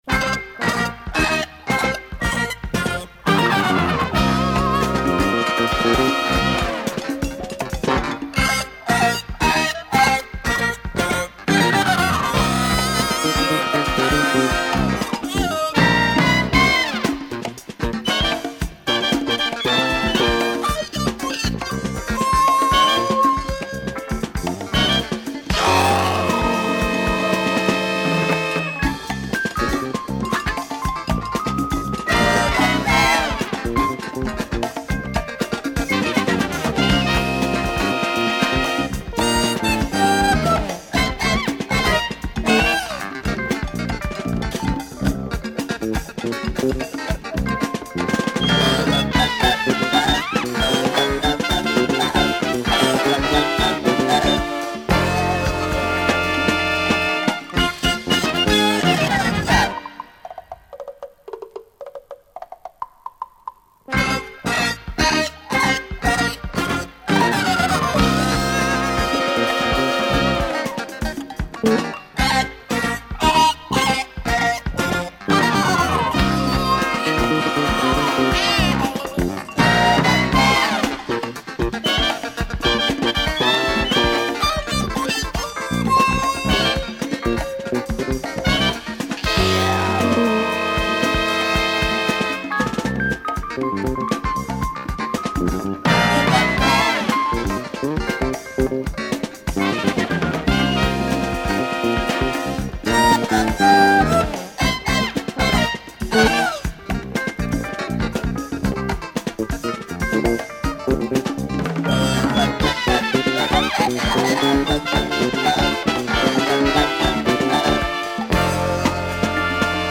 and adding some latin vibes to their groove.